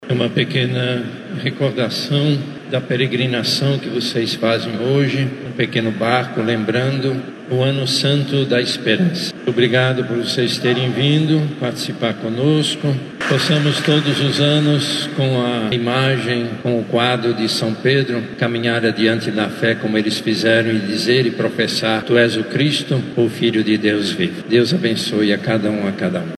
Durante a homilia, o arcebispo de Manaus, Cardeal Leonardo Steiner, destacou a trajetória do apóstolo: suas quedas, reconciliações e o martírio que selou seu testemunho de fé.